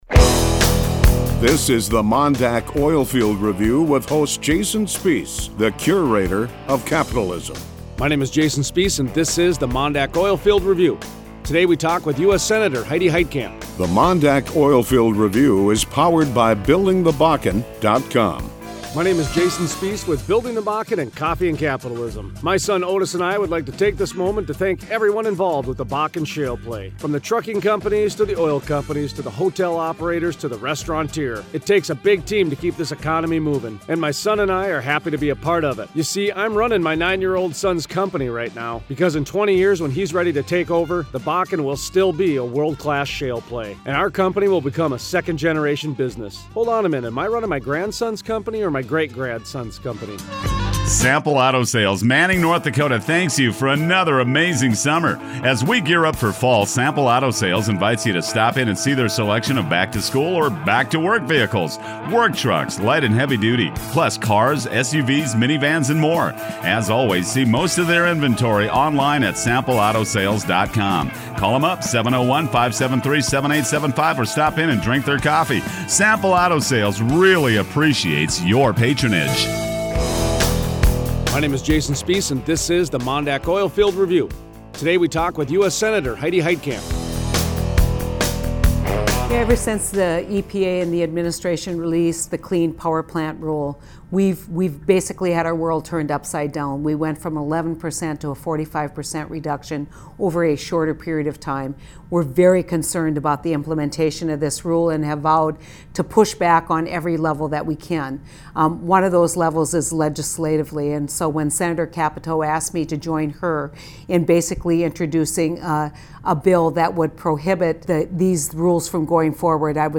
Thursday 10/29 Interview: US Senator Heidi Heitkamp Explains a resolution, if enacted into law, it would nullify the Clean Power Plan, including any portions of the regulations that have already gone into effect.